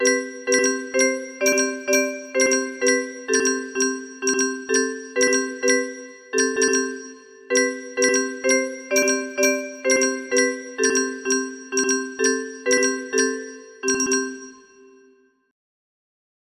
inno alla gioia music box melody